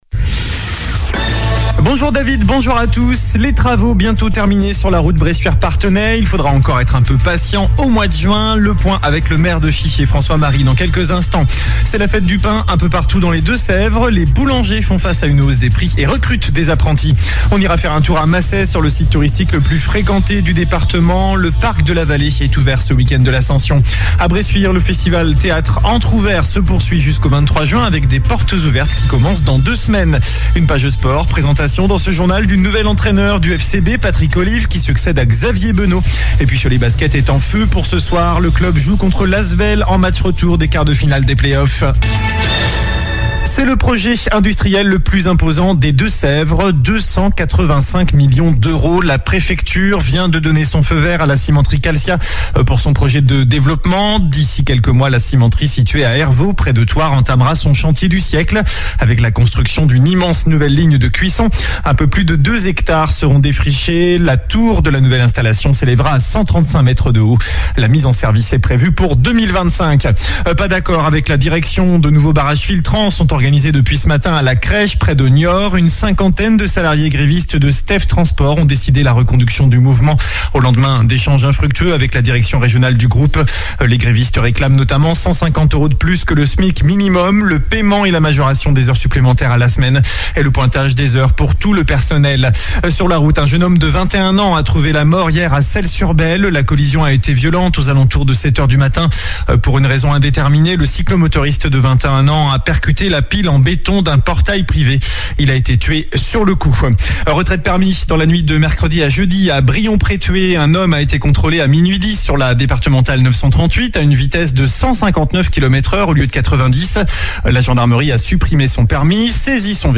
JOURNAL DU VENDREDI 27 MAI ( MIDI )